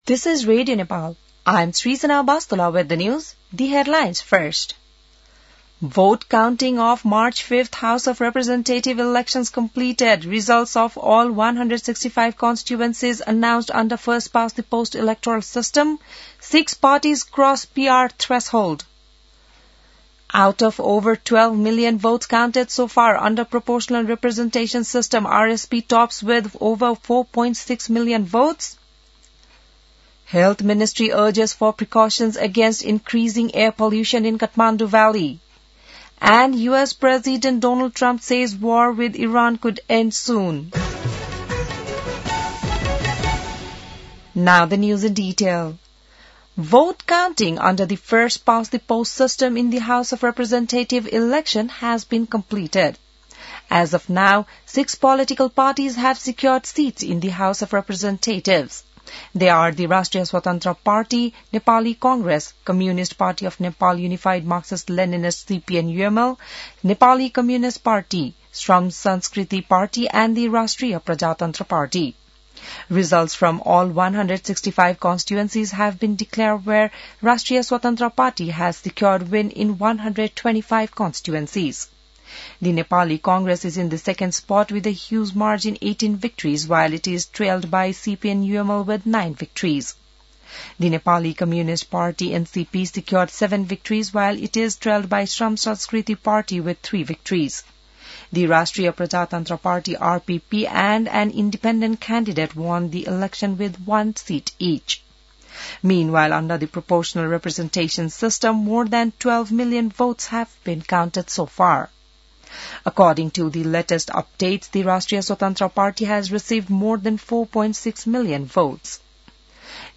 An online outlet of Nepal's national radio broadcaster
बिहान ८ बजेको अङ्ग्रेजी समाचार : २६ फागुन , २०८२